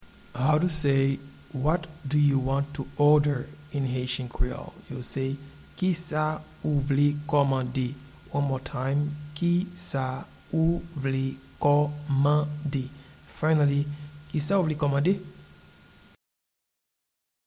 Pronunciation and Transcript:
What-do-you-want-to-order-in-Haitian-Creole-Kisa-ou-vle-komande.mp3